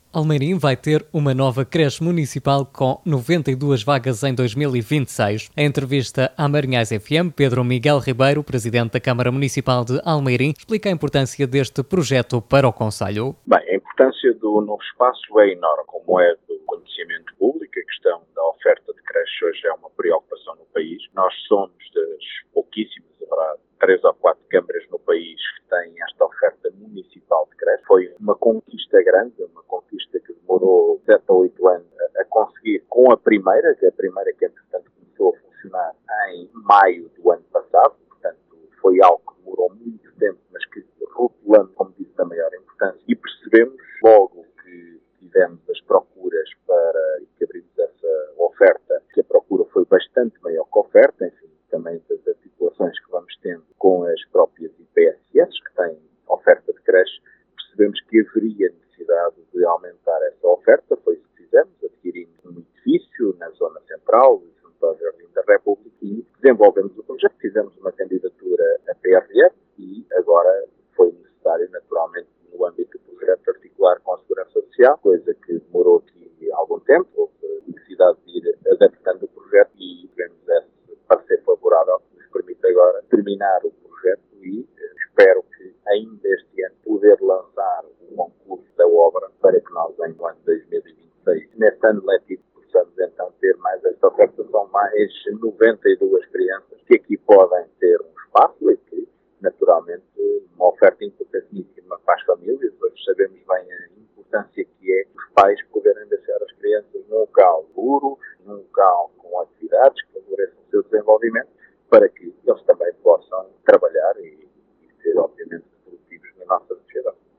Escute, aqui, as declarações de Pedro Miguel Ribeiro, Presidente da Câmara Municipal de Almeirim: